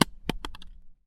На этой странице собраны разнообразные звуки, связанные с манго: от мягкого разрезания ножом до сочного откусывания.
Звук падающего плода на пол